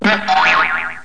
00210_Sound_COLHER.mp3